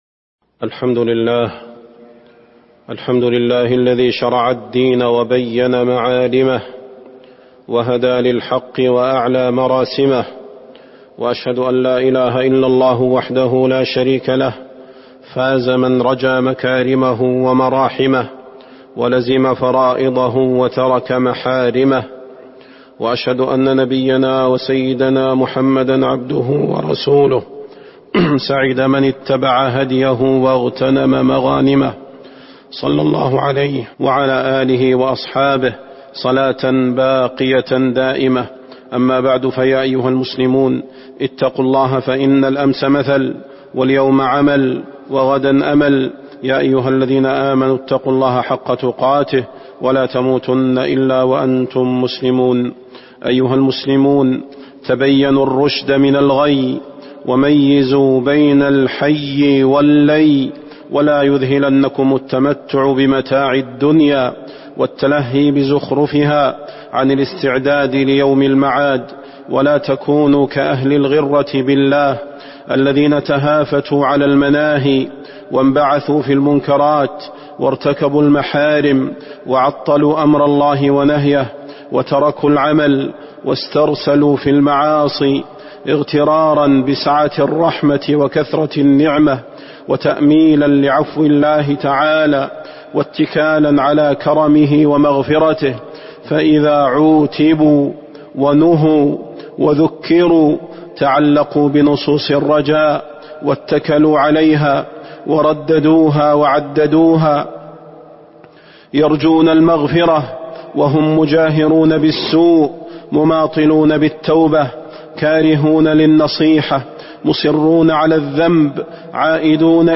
فضيلة الشيخ د. صلاح بن محمد البدير
تاريخ النشر ٩ ربيع الأول ١٤٤٣ هـ المكان: المسجد النبوي الشيخ: فضيلة الشيخ د. صلاح بن محمد البدير فضيلة الشيخ د. صلاح بن محمد البدير الرجاء الكاذب